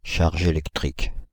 Ääntäminen
Ääntäminen France (Île-de-France): IPA: /ʃaʁ.ʒ‿e.lɛk.tʁik/ Haettu sana löytyi näillä lähdekielillä: ranska Käännös Konteksti Substantiivit 1. electric charge fysiikka, sähkö Suku: f .